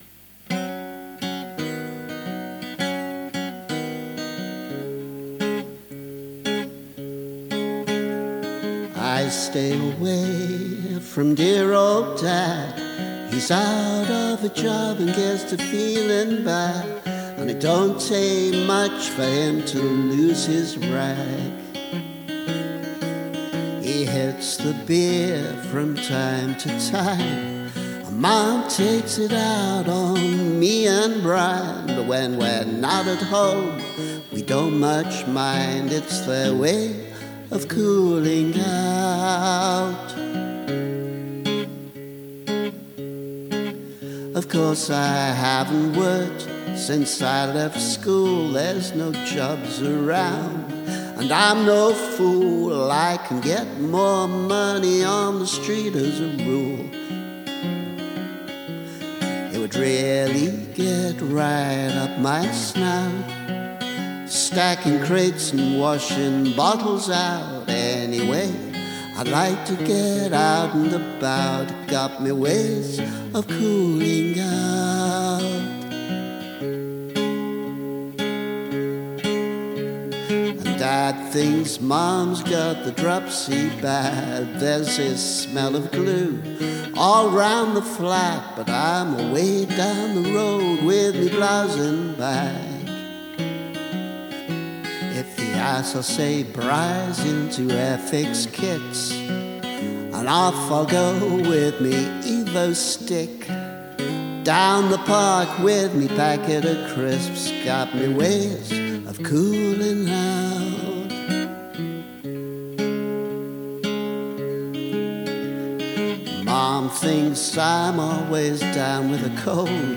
This is a sort of West London blues for the 1980s that probably demands an authentic glo’al stop here and there, but I don’t think I could carry that off, in spite of many years living surrounded by Londoners.